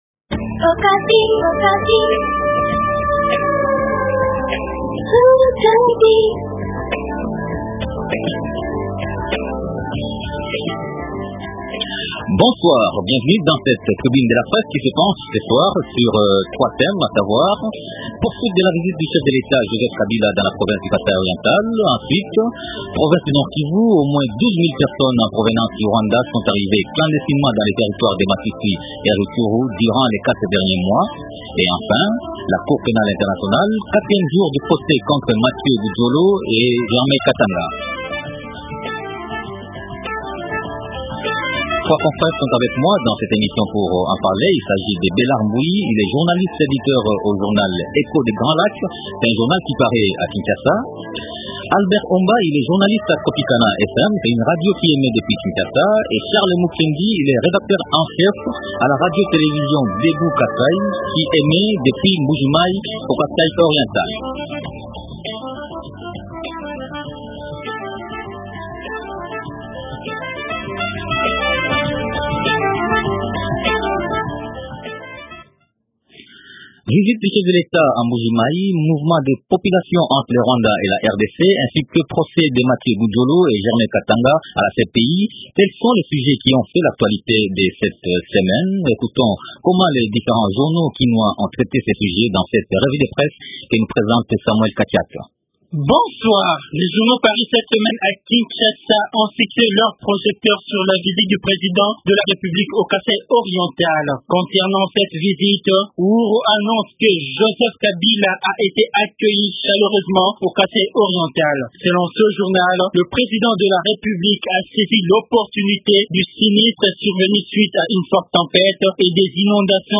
Trois thèmes sont au centre de la tribune de la presse de ce soir :rn1. Poursuite de la visite du chef de l’Etat Joseph Kabila dans la province du Kasaï Oriental.